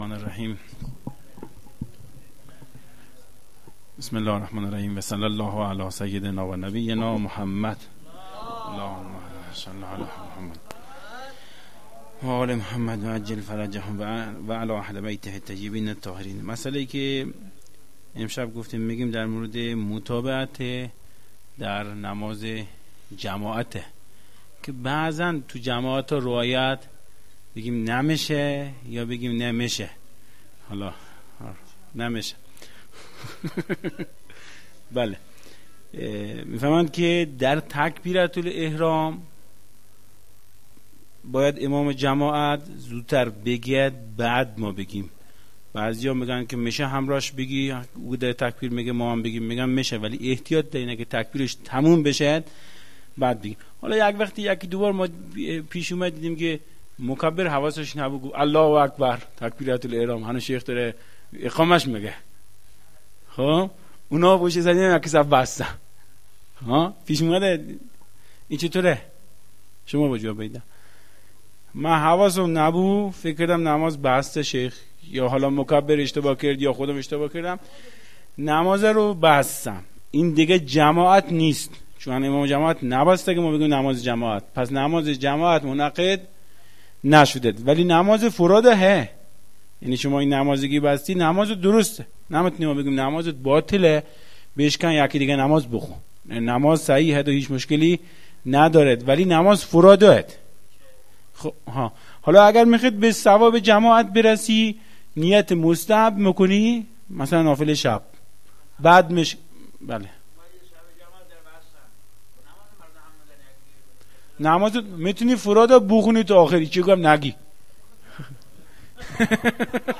بیان احکام